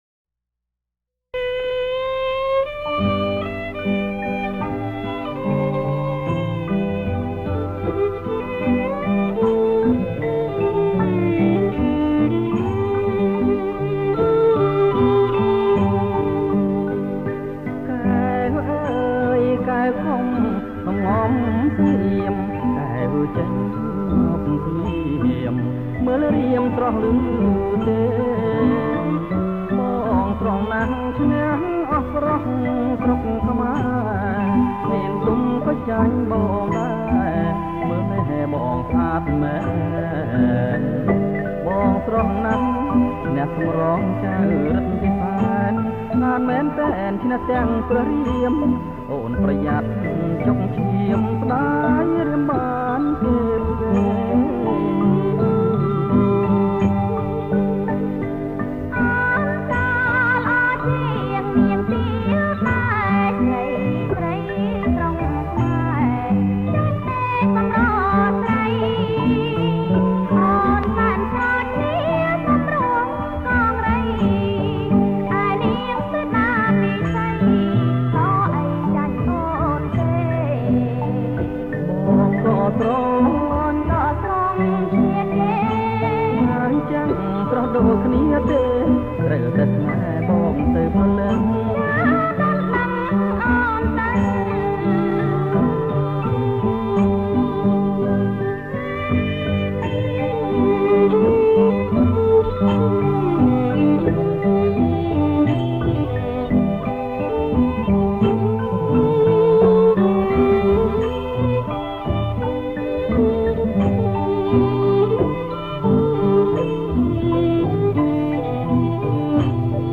• ប្រគំជាចង្វាក់ រាំក្បាច់